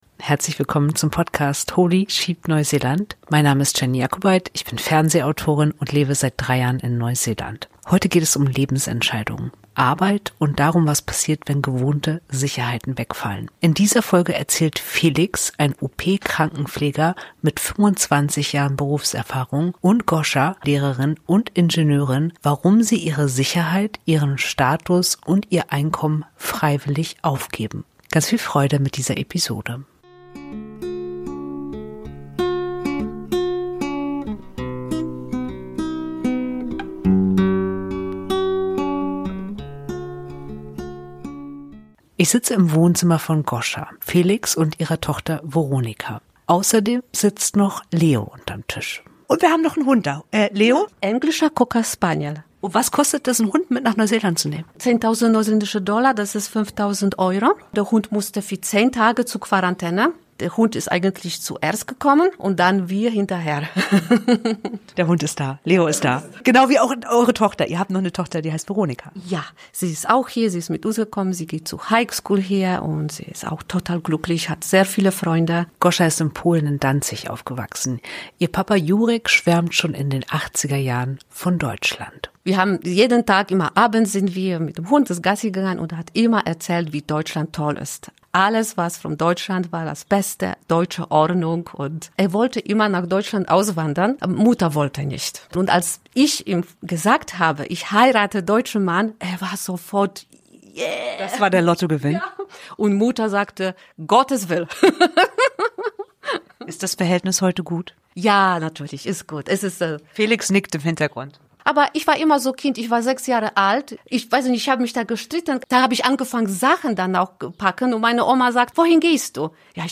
In dieser Folge erzählen eine Beamtin und ein Krankenpfleger, warum sie Deutschland verlassen haben.
Es geht um Sicherheit und ihre Grenzen, um Entscheidungen ohne Garantie und um die Frage, wann ein Leben zwar vernünftig erscheint, sich aber nicht mehr richtig anfühlt. Ein Gespräch über Zweifel, Verlust und den Mut, einen Bruch zuzulassen, ohne ihn zu verklären.